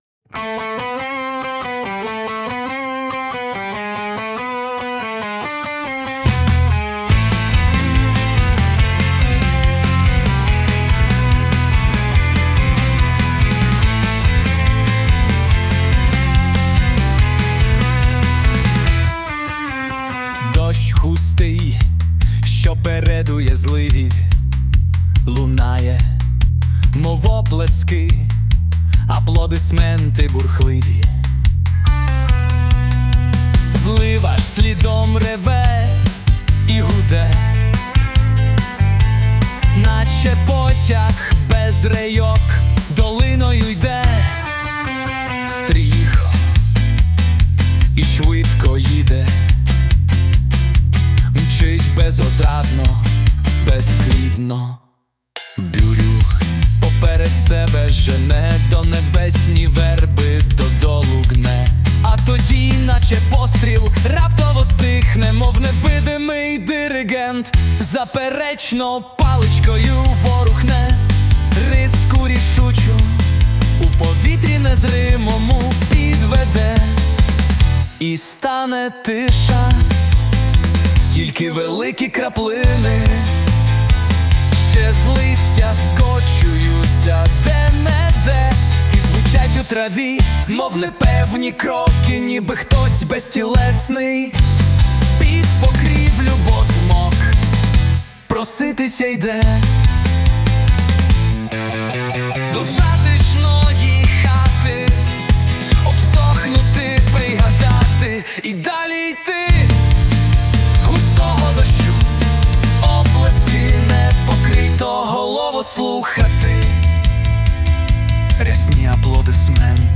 (вірш мій, музика і виконання - ШІ)